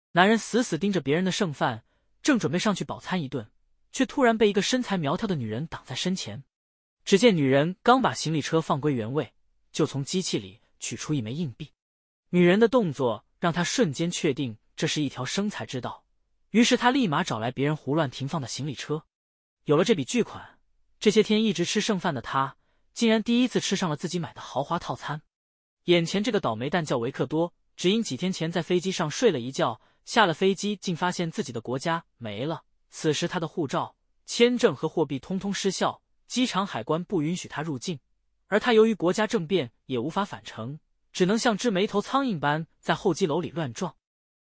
完全免费，超强的文字转语音工具，多种经典音色任你选择，详细使用教程！
测试效果如下：